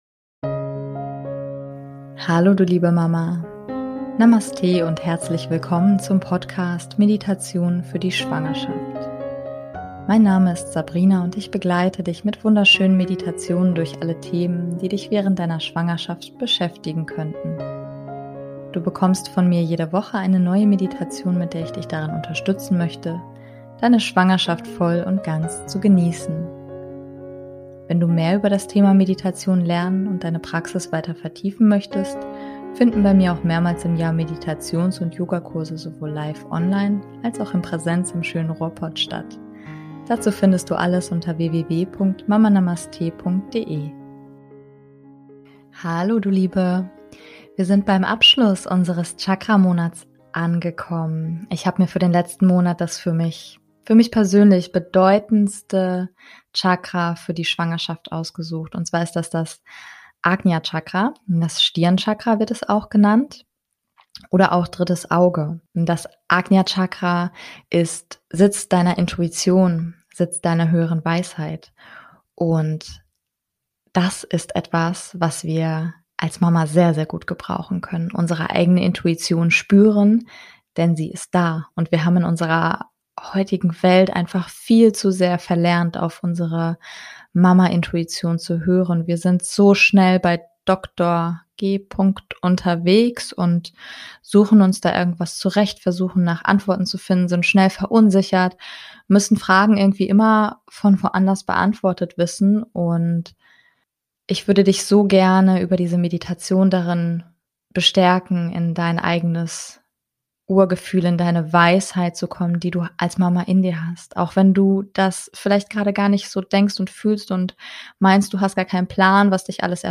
#059 - Meditation - Stirn Chakra - Intuition stärken für Schwangere und Mamas ~ Meditationen für die Schwangerschaft und Geburt - mama.namaste Podcast